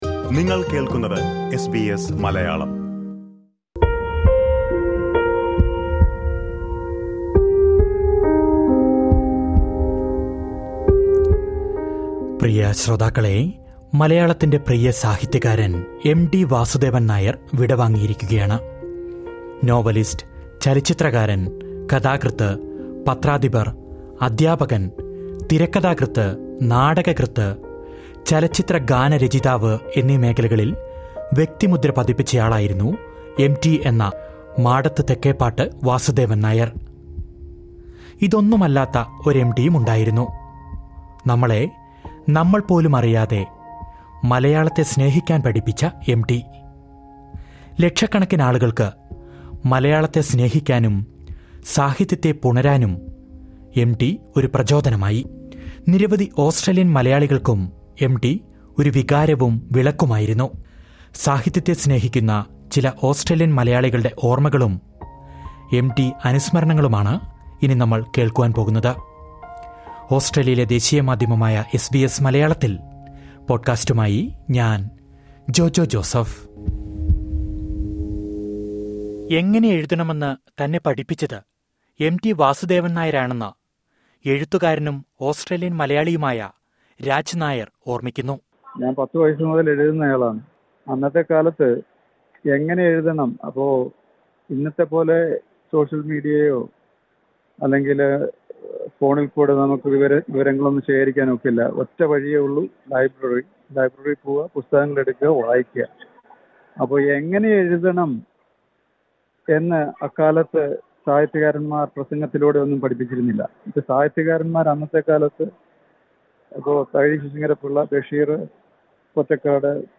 പ്രശസ്ത സാഹിത്യകാരൻ, മലയാളികളുടെ പ്രീയപ്പെട്ട എം.ടി ബുധനാഴ്ച അന്തരിച്ചു. എം.ടി വാസുദേവൻ നായരെയും അദ്ദേഹത്തിന്ററെ സൃഷ്ടികളെയും ഓസ്ട്രേലിയൻ മലയാളികൾ അനുസ്മരിക്കുന്നത് കേൾക്കാം, മുകളലിലെ പ്ലെയറിൽ നിന്നും...